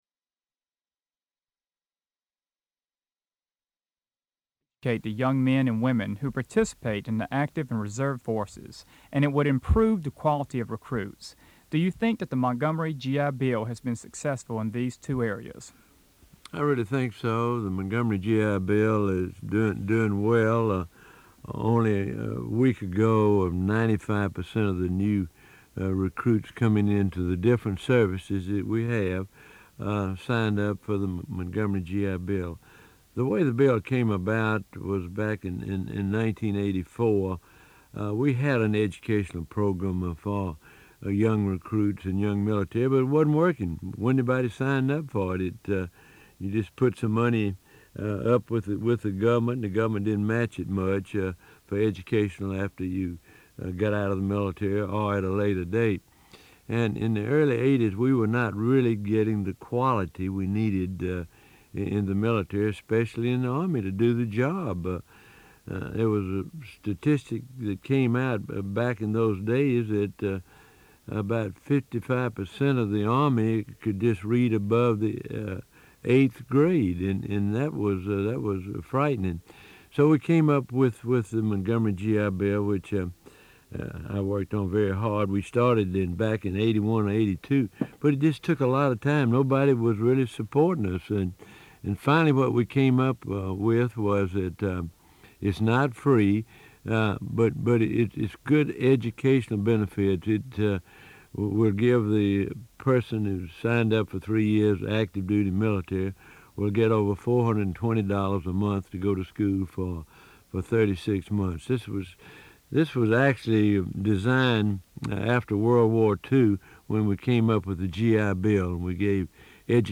Weekly Radio Addresses